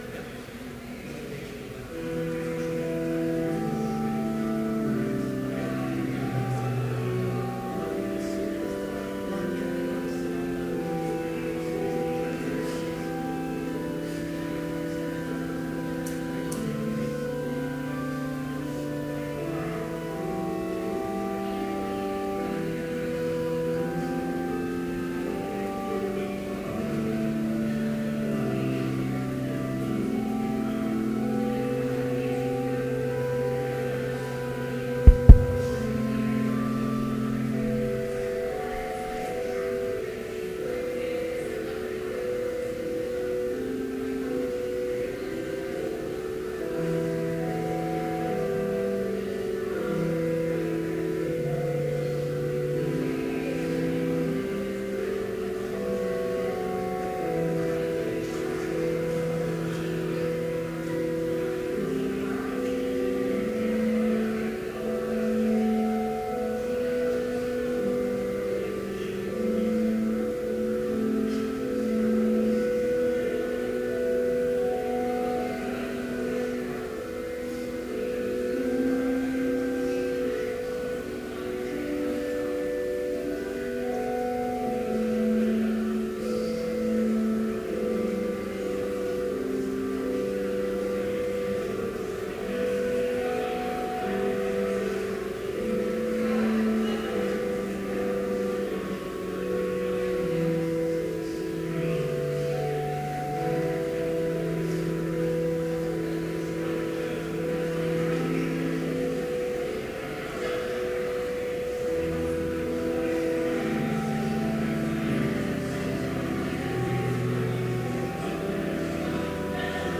Complete service audio for Chapel - February 26, 2014